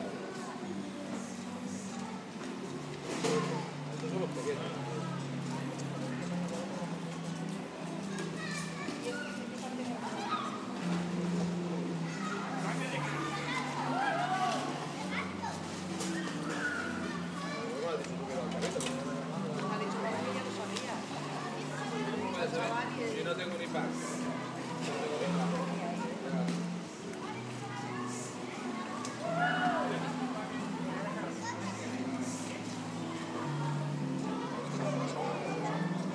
Piscina lounge en el hotel